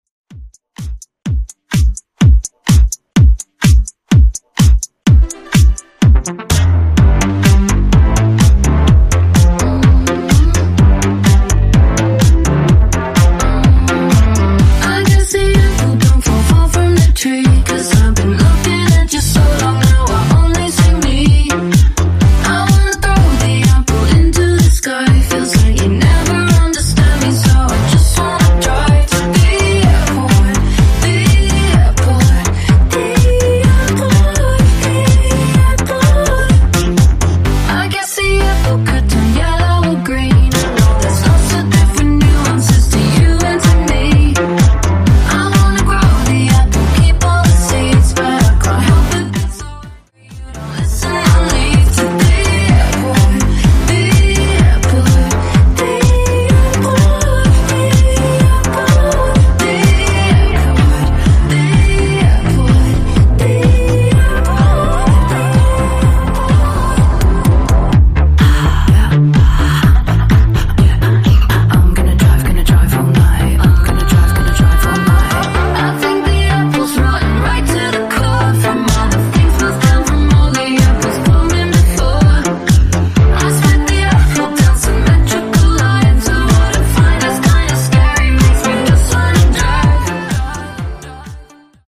Genres: DANCE , HIPHOP